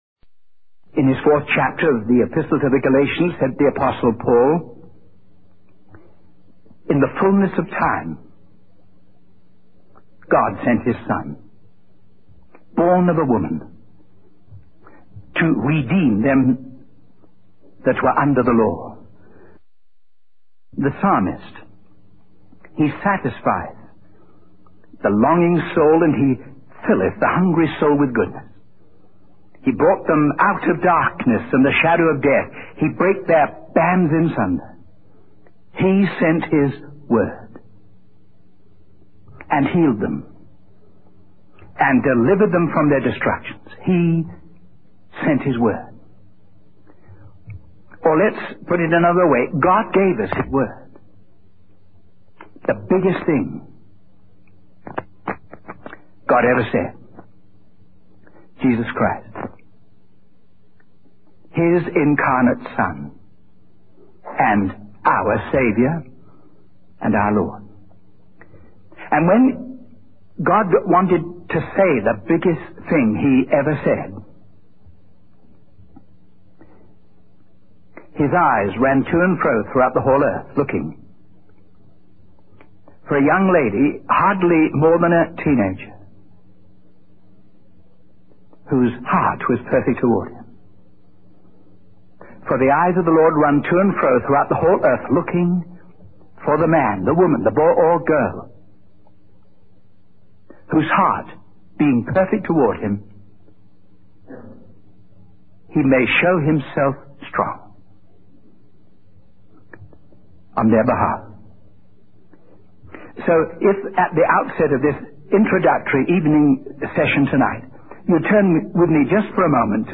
In this sermon, the preacher emphasizes that God has chosen individuals from seemingly ordinary and unpromising backgrounds to serve Him.